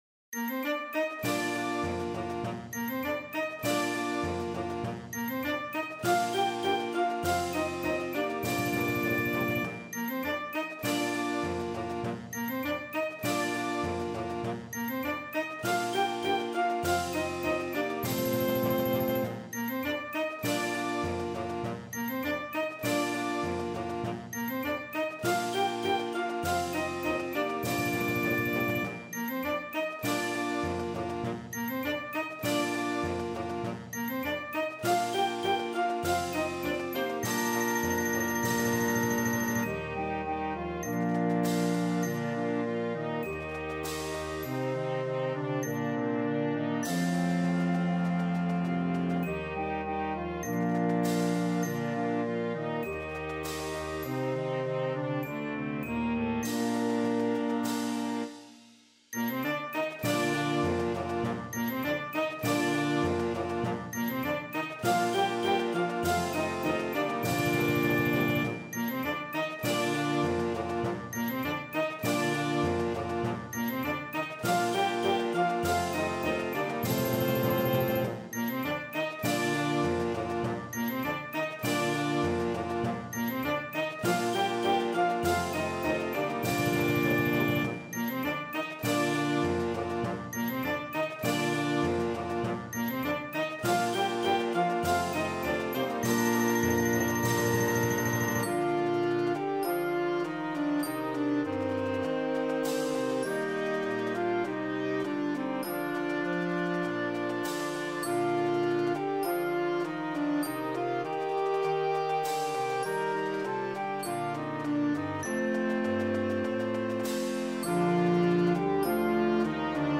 is a wonderful beginning march